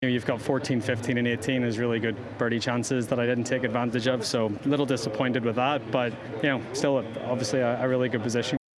McIlroy speaks to Sky Sports News